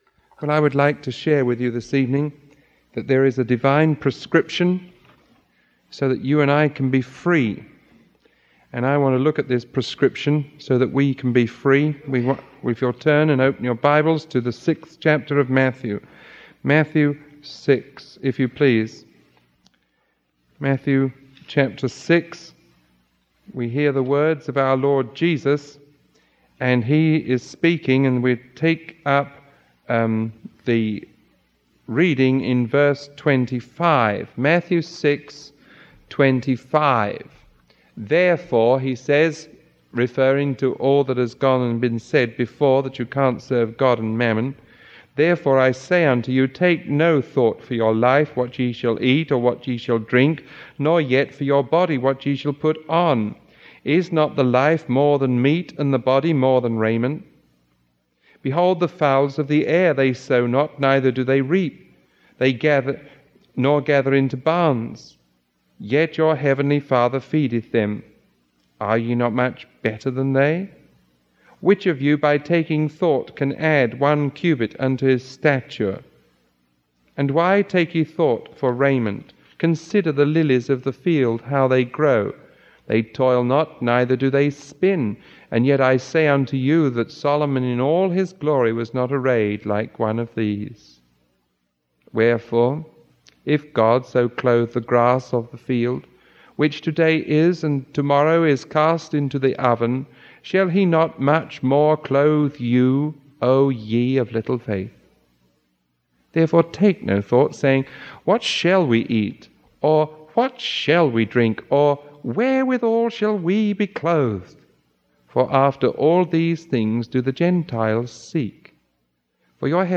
Sermon 0095A recorded on October 3